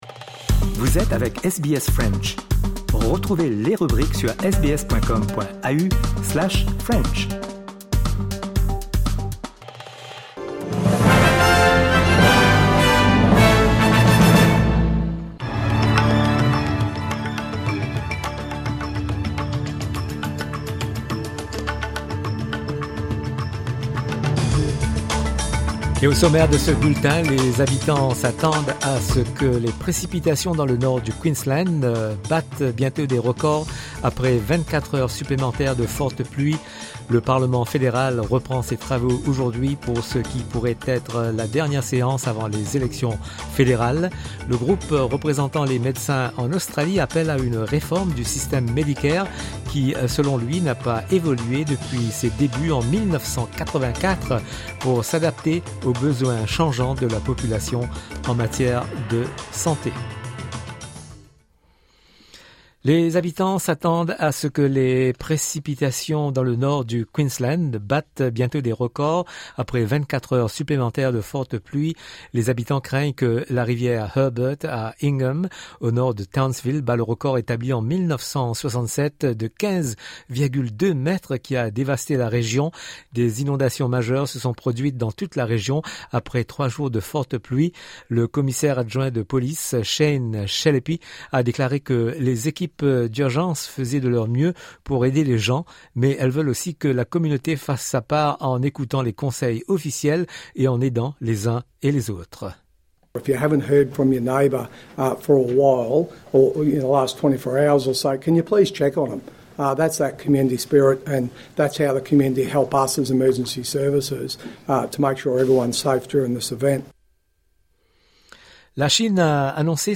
SBS French News